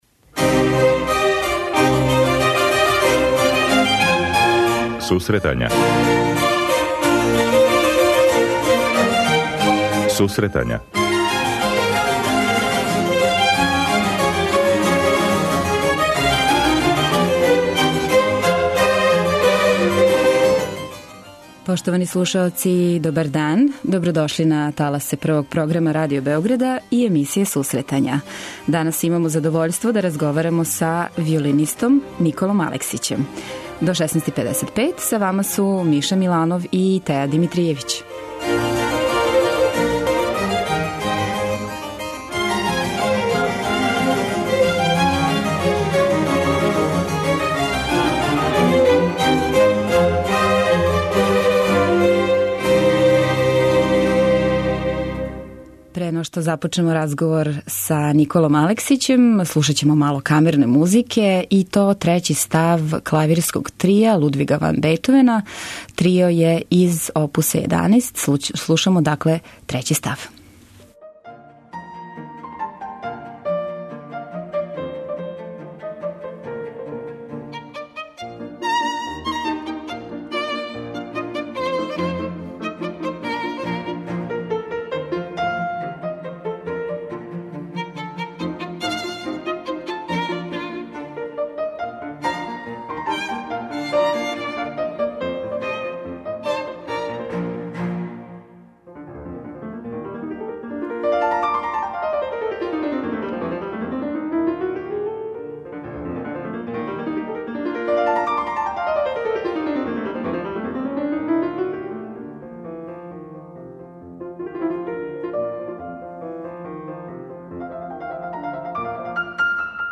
Гост емисије биће виолиниста